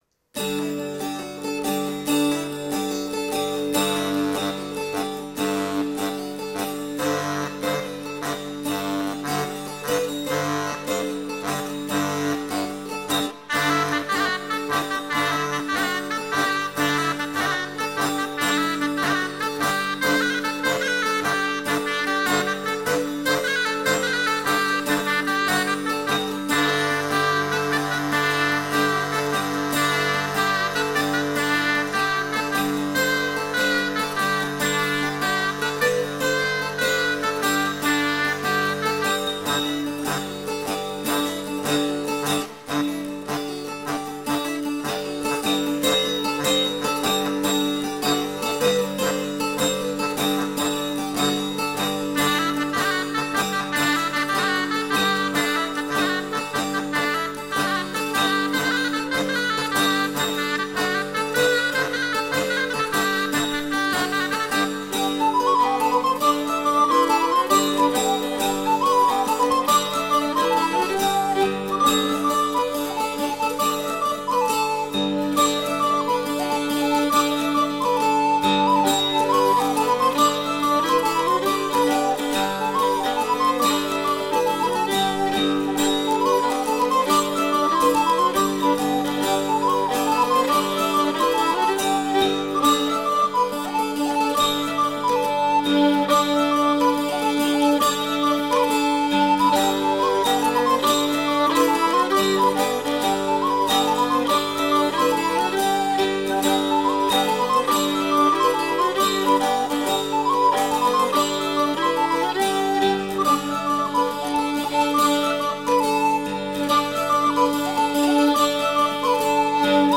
Musiques à danser
accordéon diatonique, percus
guitare, bouzouki
deux airs traditionnels franc-comtois